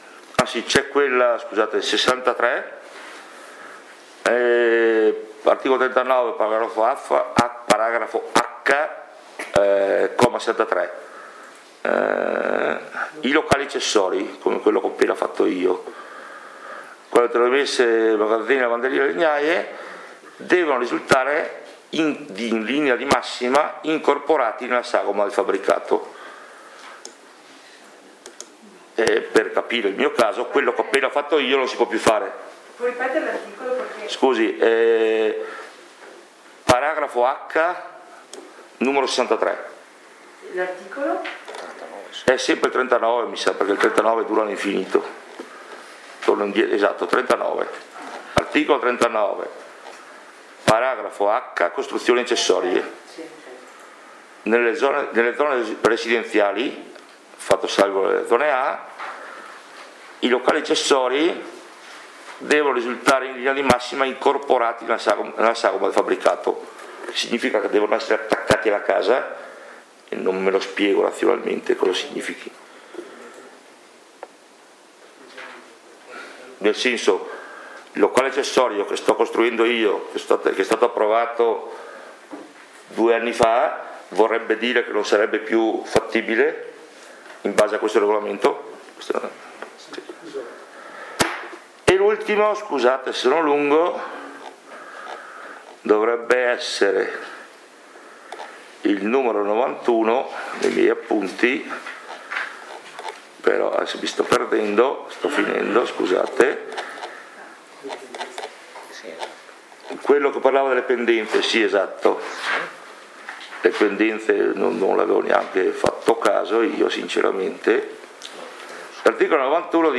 CONSIGLIO COMUNALE DI FALCADE, REGISTRAZIONE INTEGRALE DURATA 1h33m
FALCADE Oggi alle 17.30 consiglio comunale convocato dal sindaco Michele Costa. All’ordine del giorno l’approvazione della prima variante del Piano di Assetto del Territorio, del nuovo regolamento edilizio e del Documento Unico di Programmazione per il triennio 2020-23.